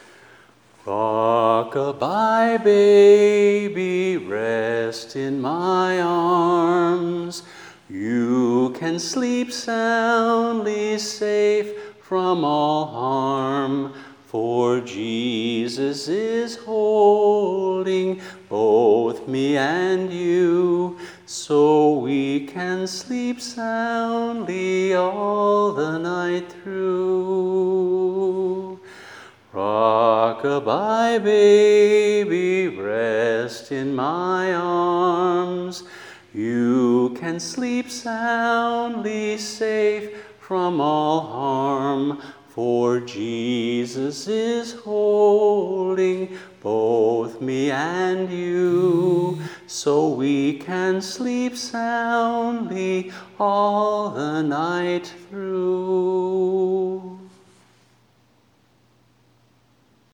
(Scriptural Nursery Rhymes)
a cappella